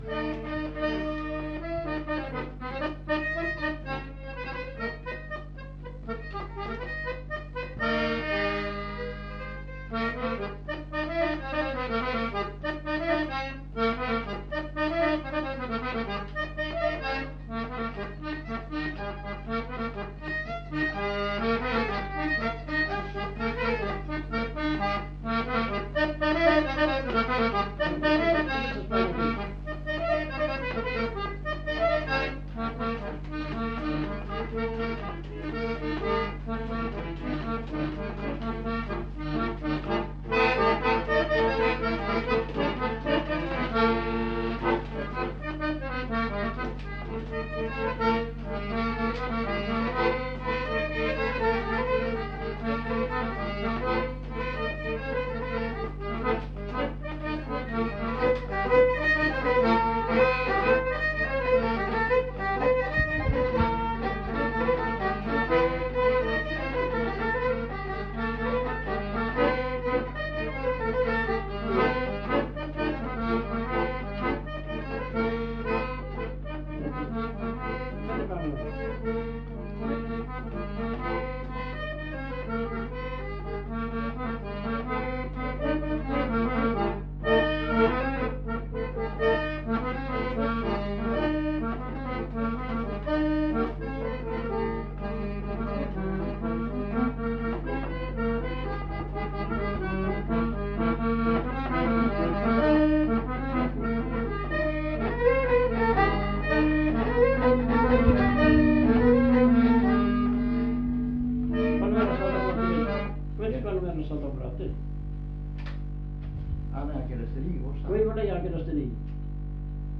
Répertoire de danses des Petites-Landes interprété au violon et à l'accordéon chromatique
Polka